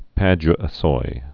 (păj-ə-soi)